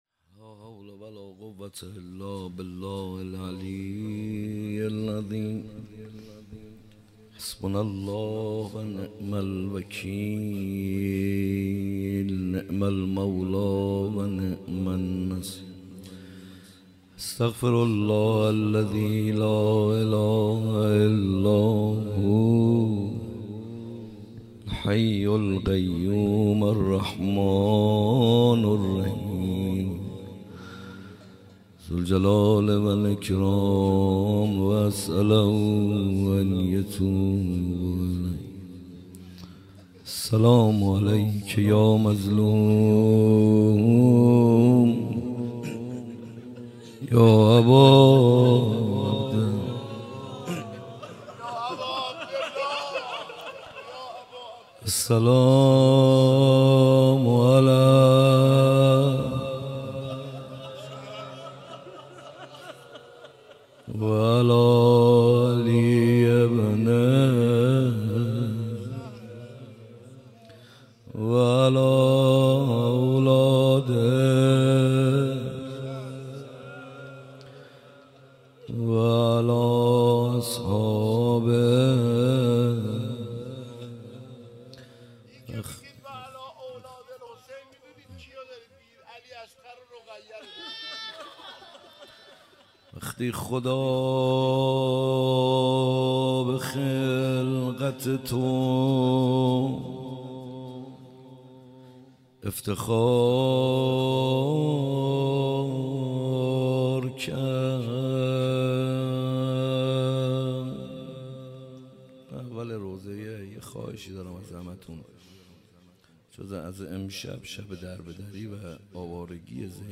روضه ورود کاروان به کربلا